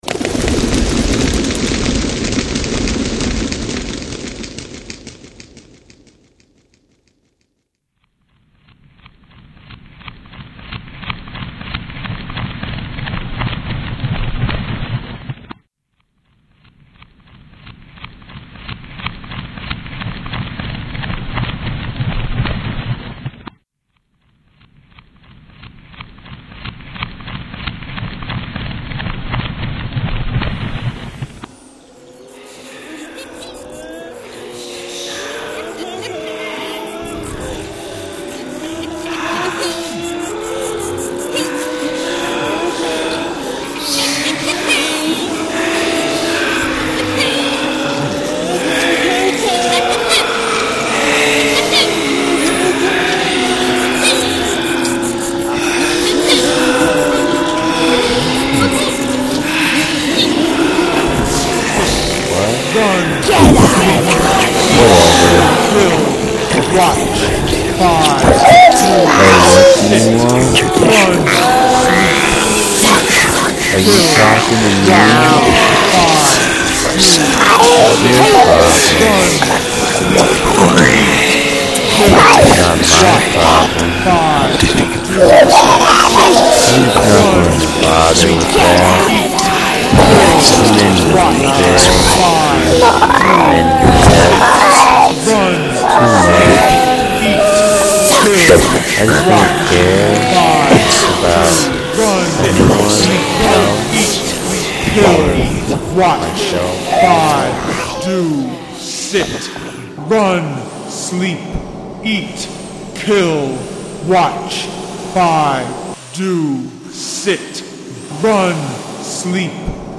Through voiceovers, originally recorded sound and sampled music, the desired effect is acheived.
First, a flock of birds fly away.
Each head has its solo with the corresponding sound (Control, Anger, Depression, Greed, Pride, Apathy).